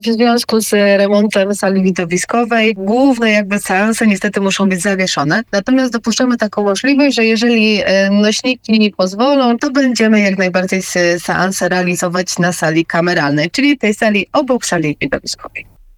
Radio BIELSKO Przeboje non-stop